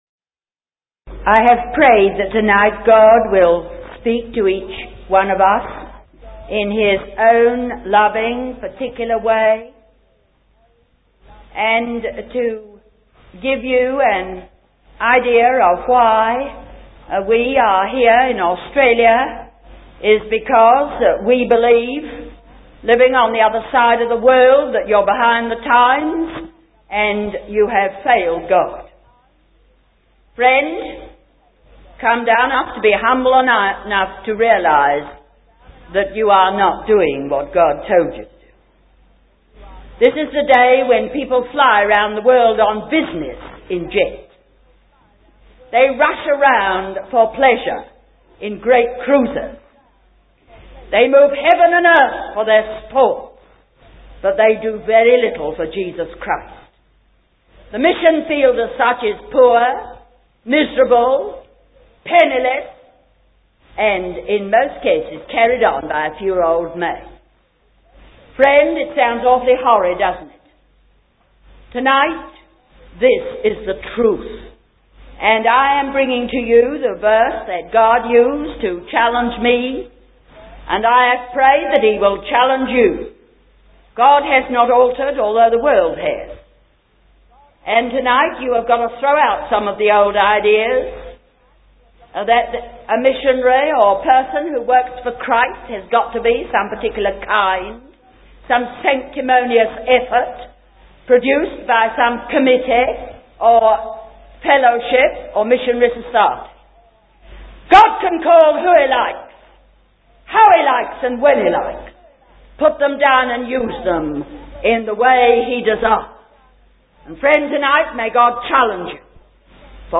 Gladys Aylward challenges the audience in Australia to recognize their complacency and the urgent need for action in spreading the Gospel.